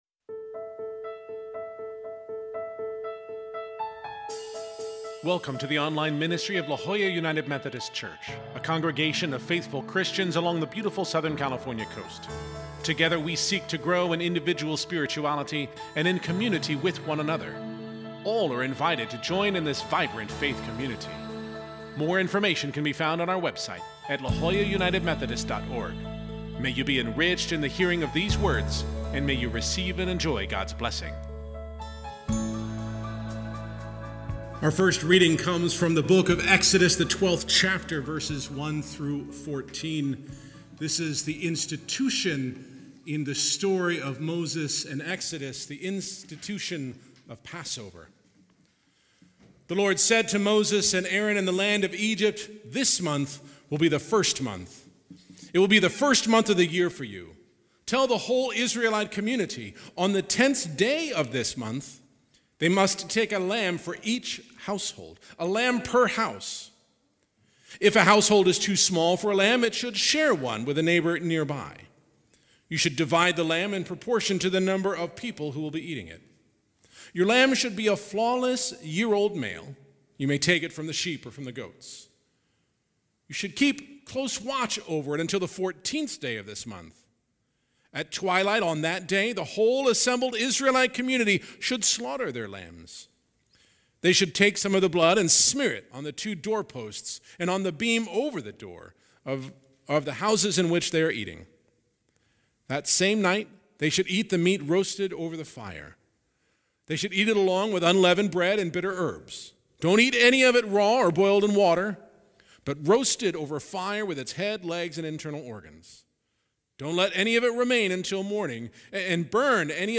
We join together with the people of Pacific Beach UMC for an in-person and online service to confess and be pardoned, to […]